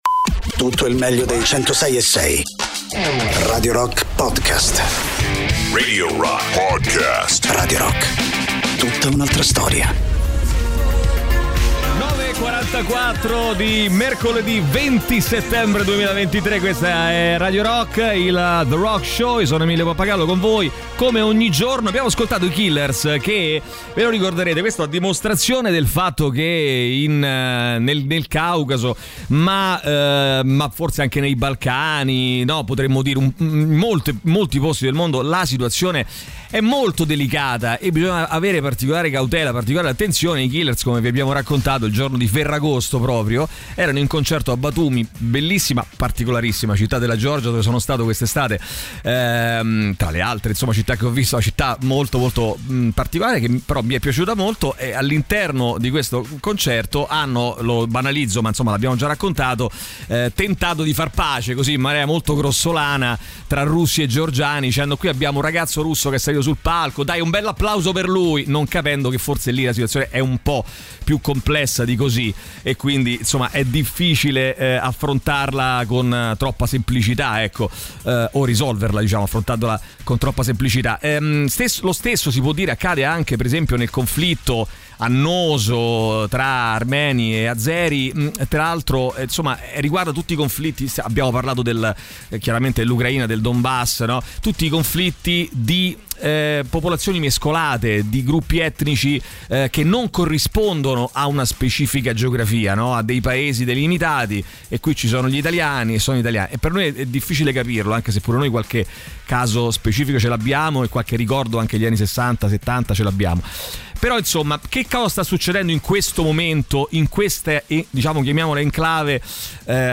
Interviste
in collegamento telefonico